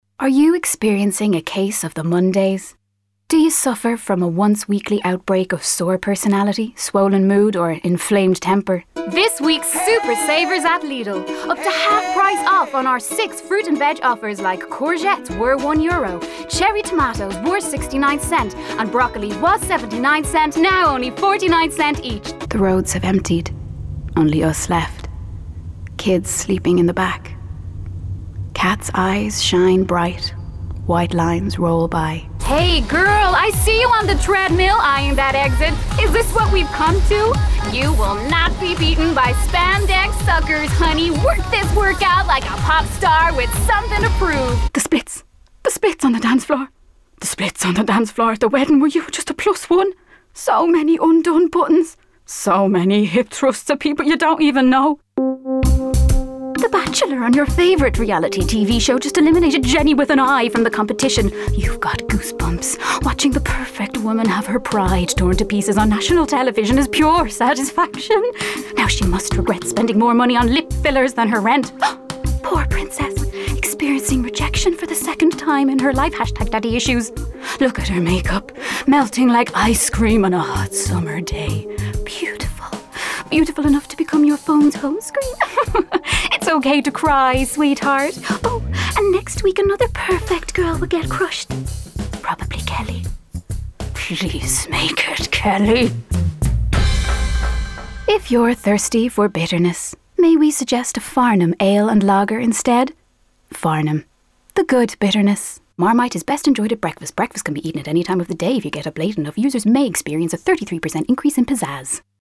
Female
Rode NT
20s/30s, 30s/40s
Irish Dublin Neutral, Irish Neutral